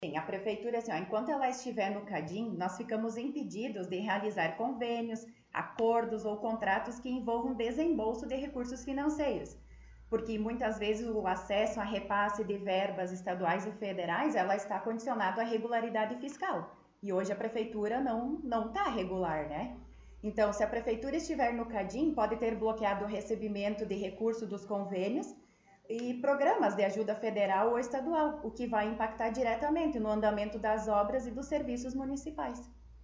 Secretária Municipal de Planejamento concedeu entrevista